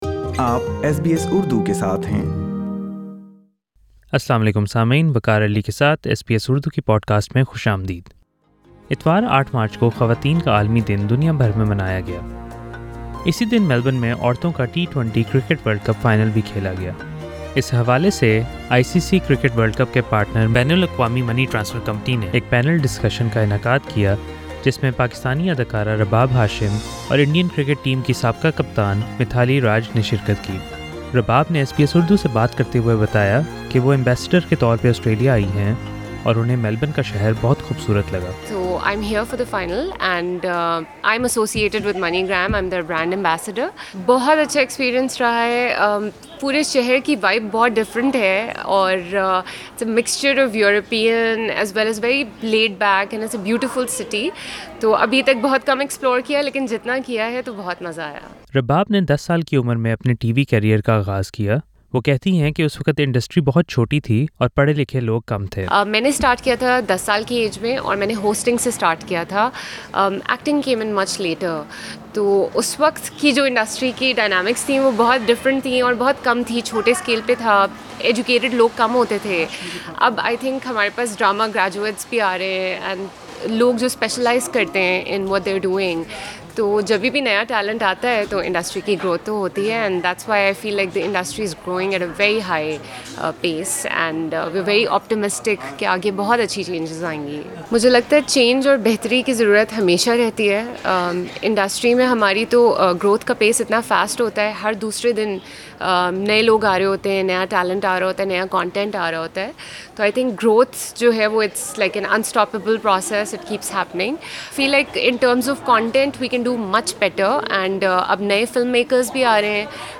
Pakistani actress Rabab Hashim took part in a panel discussion hosted on International women’s day where she shared the stage with former Indian Cricket captain Mithali Raj talking about their experiences and challenges of being a woman.
Mithali Raj & Rabab Hashim taking part in women's day panel discussion.